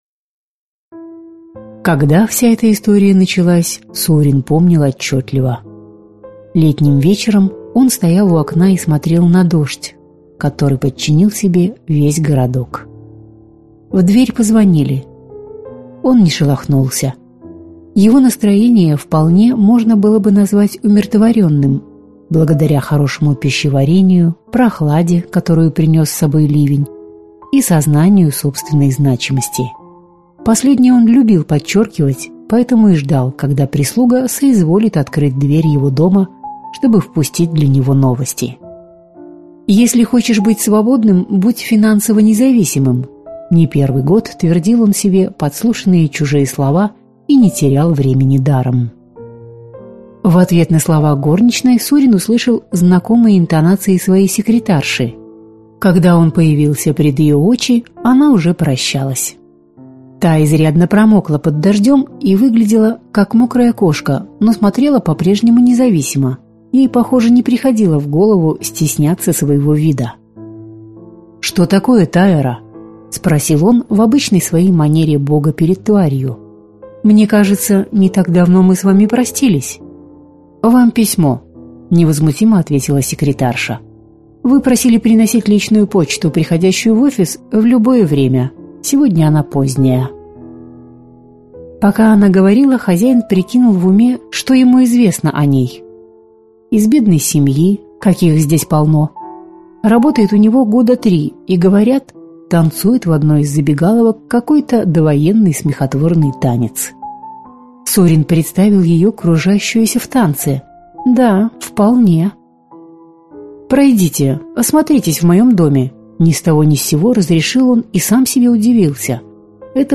Аудиокнига Как стать тенью | Библиотека аудиокниг